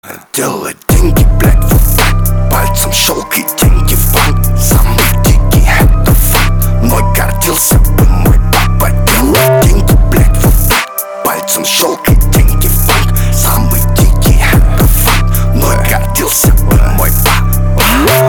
• Качество: 320, Stereo
мощные басы
качающие
мрачные
Cloud Rap
Alternative Rap
устрашающие